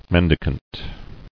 [men·di·cant]